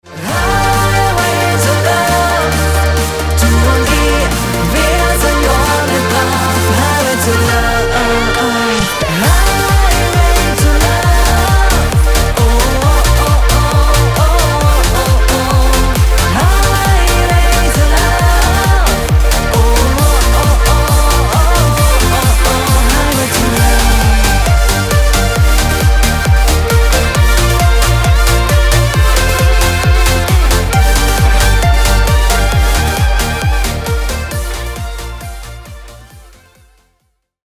eine gefühlvolle Roadstory über eine Frau